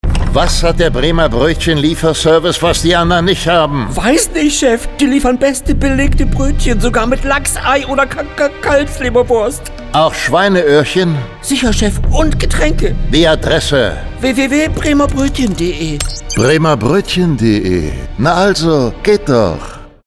Bremer-Broetchen-Gangster-Spot_1.mp3